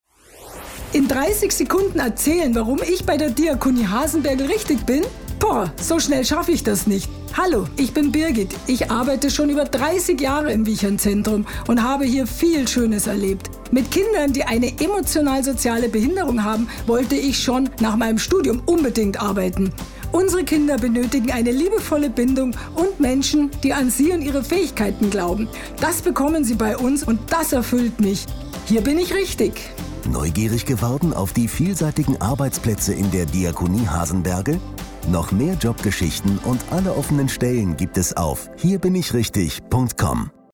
Unsere Radiostimmen 2024